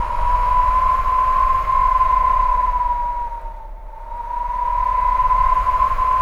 WIND HOWL2.wav